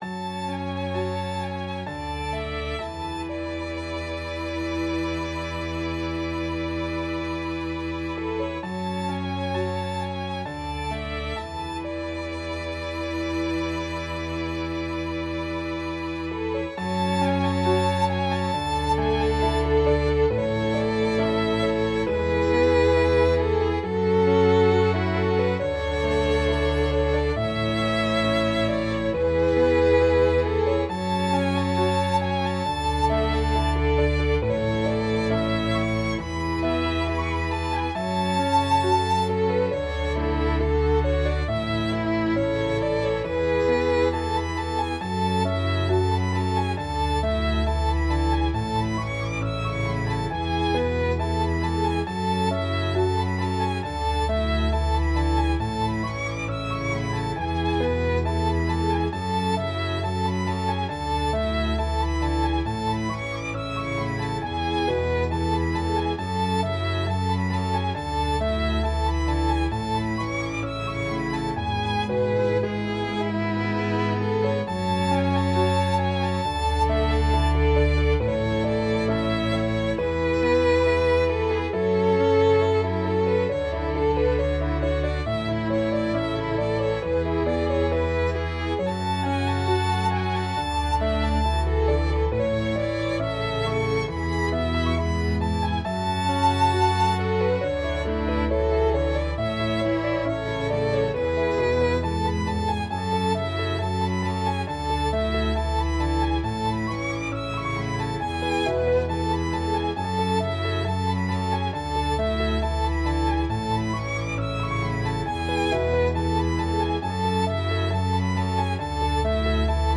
para Cordas e Piano
● Violino I
● Violino II
● Viola
● Violoncelo
● Contrabaixo